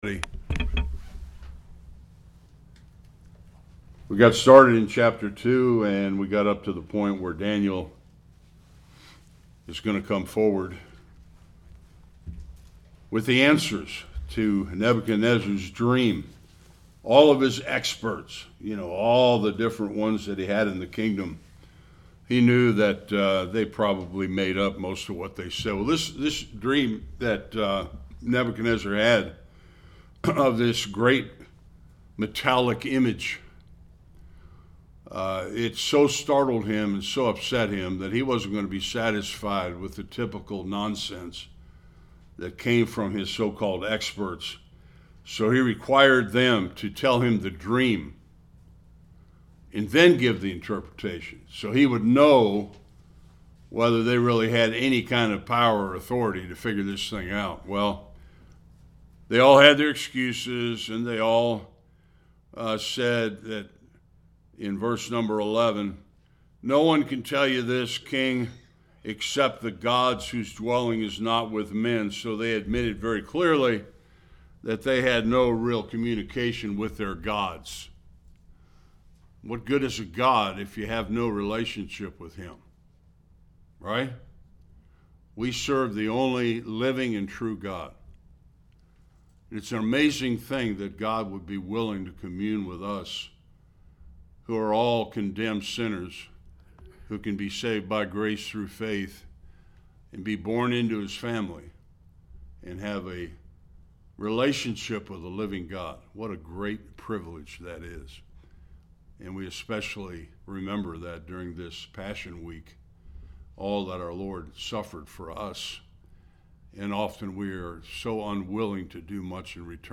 12-40 Service Type: Sunday School Daniel saved the day and described and interpreted King Nebuchadnezzar’s night vision or dream.